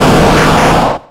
Cri de Tortank dans Pokémon X et Y.